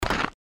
stepsnow_2.wav